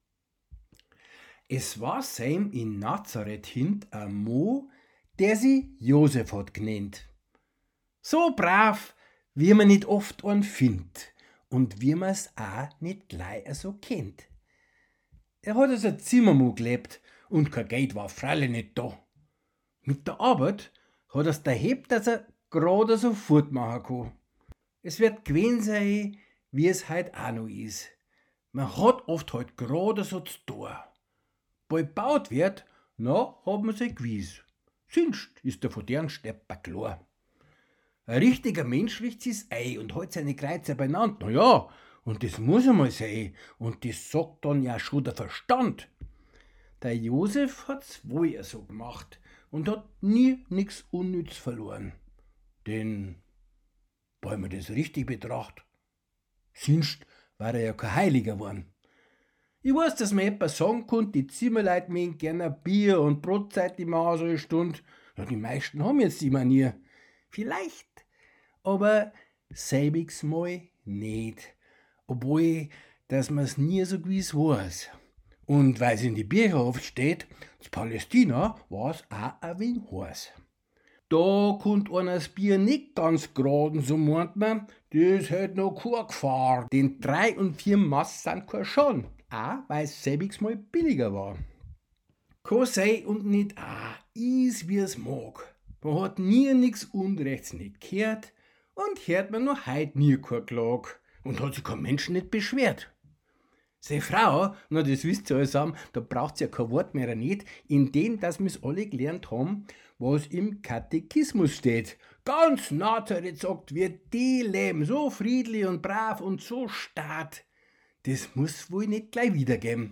An der Zither